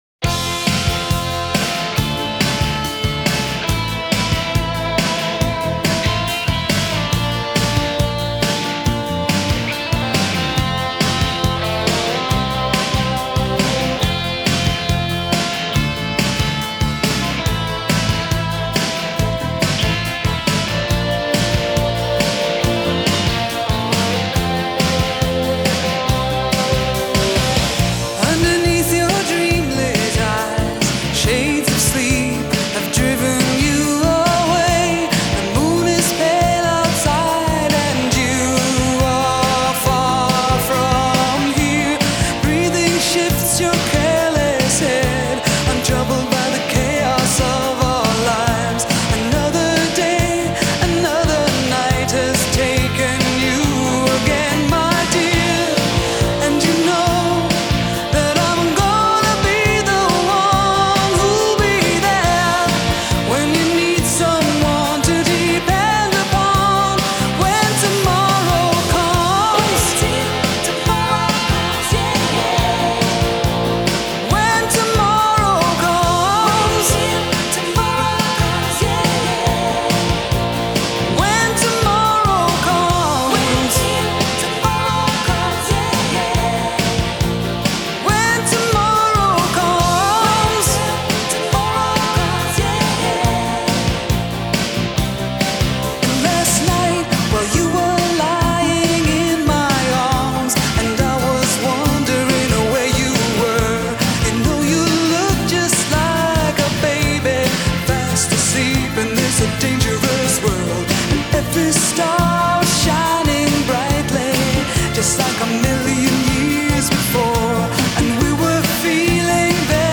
синти-поп-дуэт